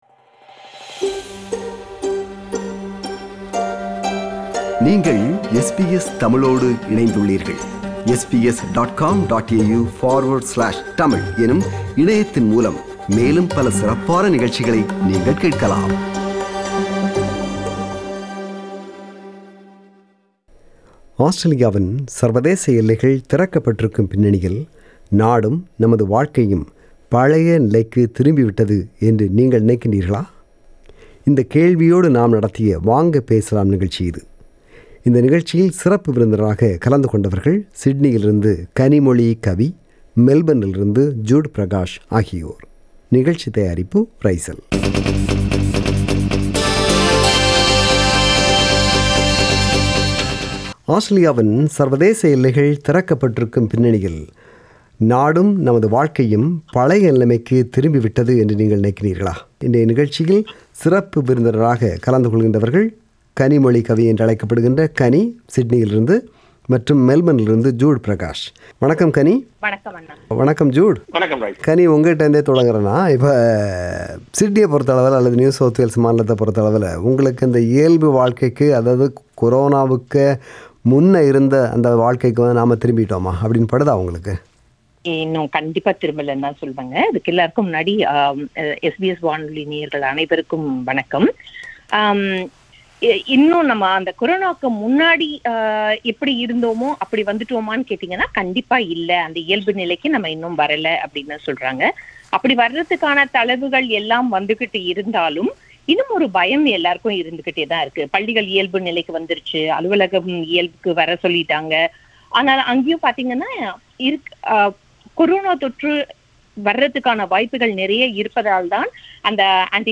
This is compilation of opinions shared by listenersin “Vanga Pesalam” program.